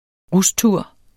Udtale [ ˈʁusˌtuɐ̯ˀ ]